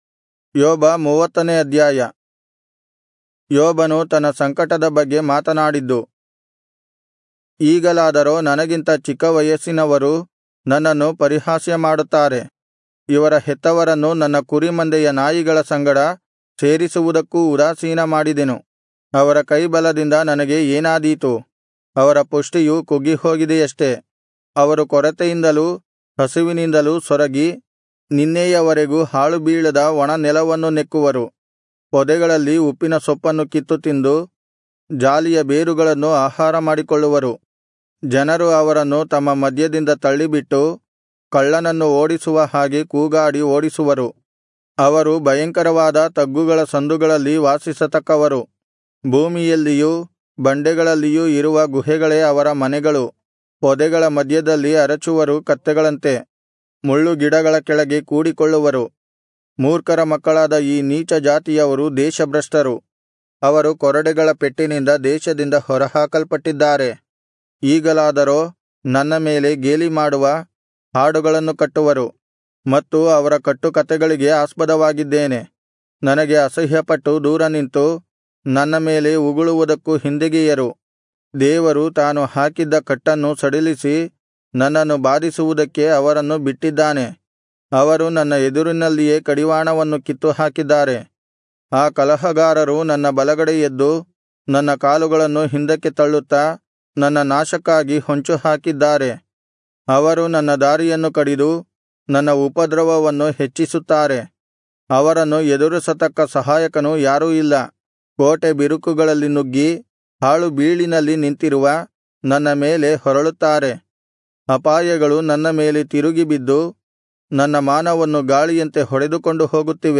Kannada Audio Bible - Job 38 in Irvkn bible version